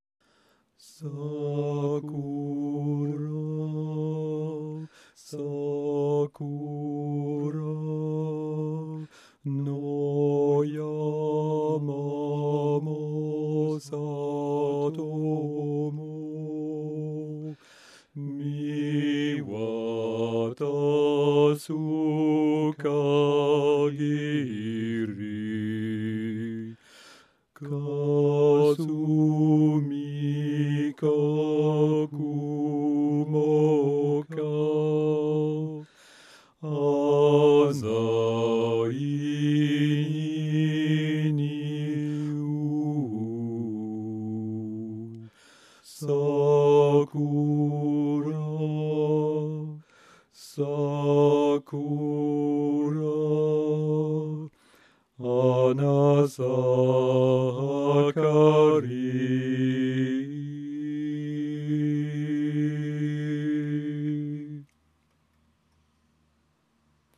Alto
SakuraAlto.mp3